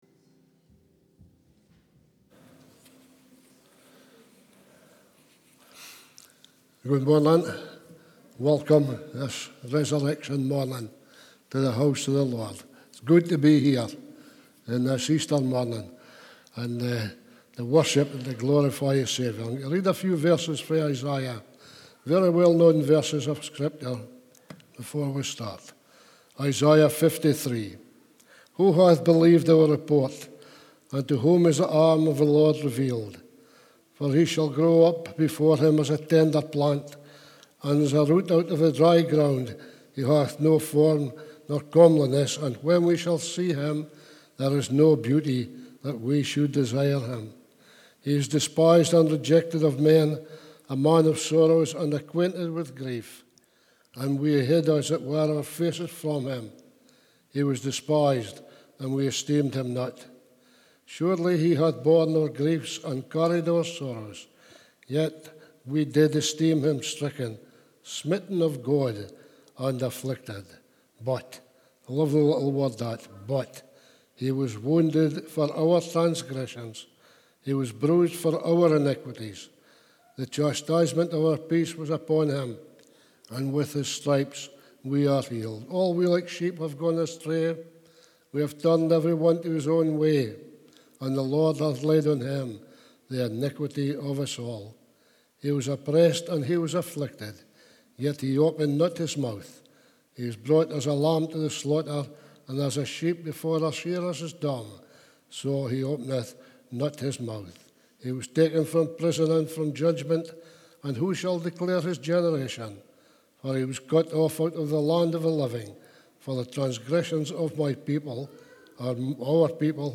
Easter Service